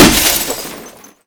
glass_break.ogg